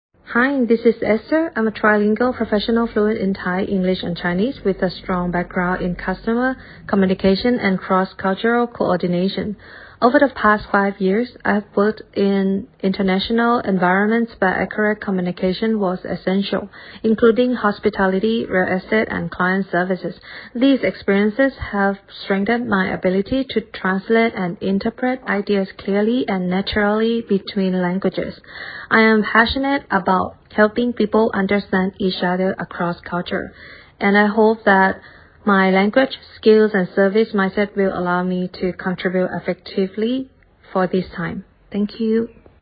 我们口译师专业自介音档试听如下，都是派出语言流利的跨国语言译者服务您：
✔英语自介音档: